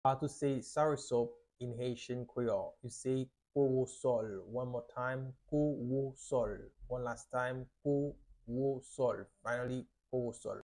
How to say "Soursop" in Haitian Creole - "Kowosòl" pronunciation by a native Haitian Teacher
“Kowosòl” Pronunciation in Haitian Creole by a native Haitian can be heard in the audio here or in the video below:
How-to-say-Soursop-in-Haitian-Creole-Kowosol-pronunciation-by-a-native-Haitian-Teacher.mp3